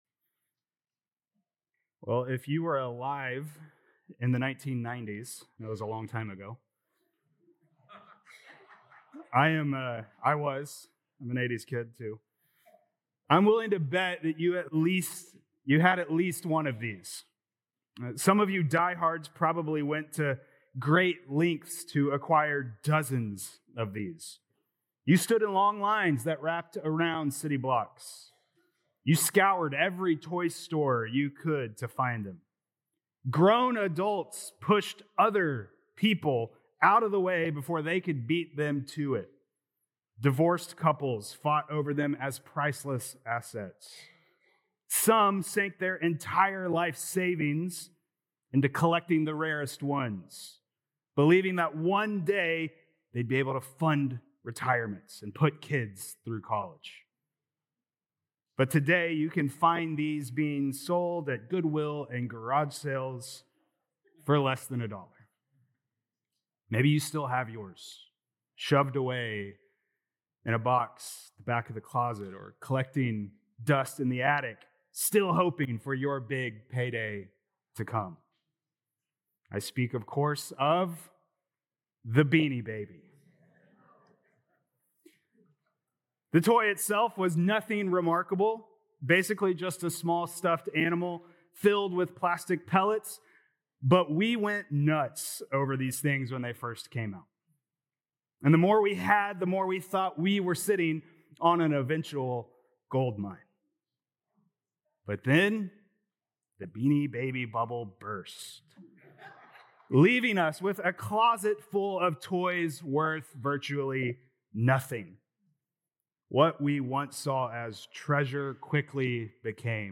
Sept 14th Sermon | Philippians 3:1-11